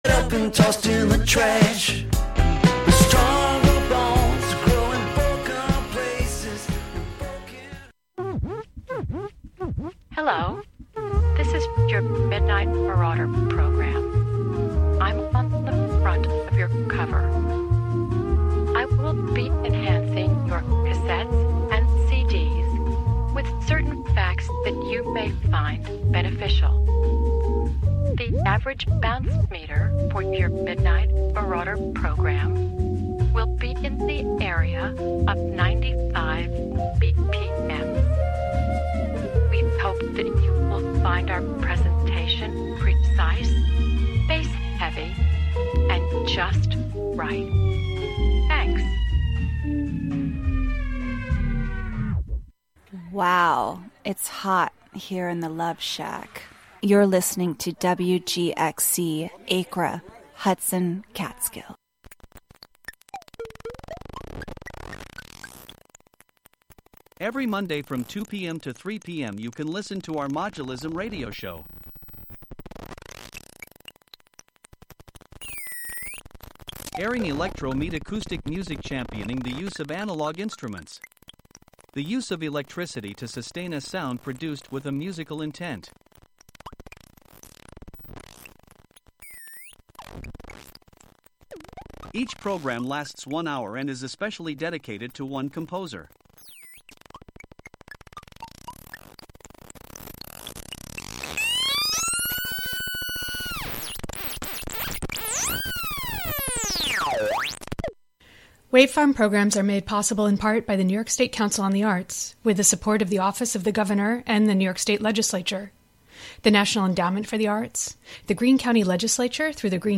Honoring the central importance of music and sound in decolonization practices. Amplifying Indigenous worldviews, knowledge systems, and sound practices.